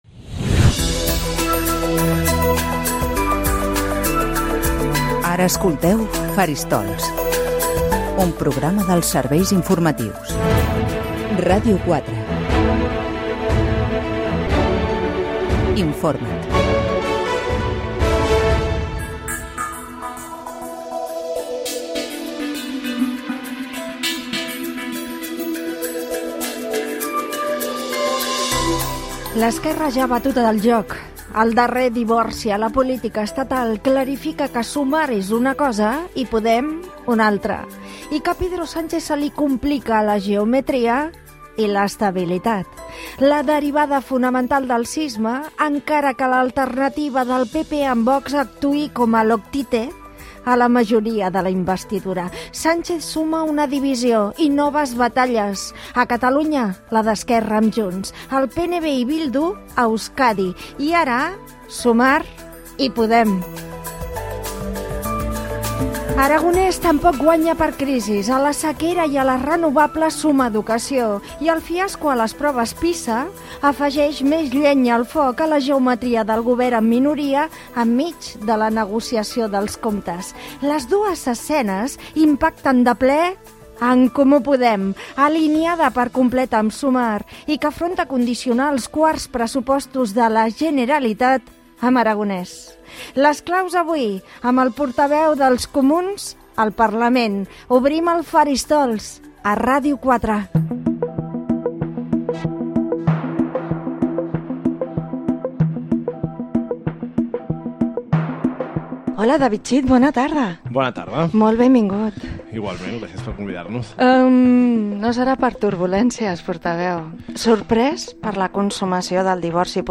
Careta del programa, resum de la situació política del moment, entrevista a David Cid, portaveu dels Comuns al Parlament de Catalunya.
Informatiu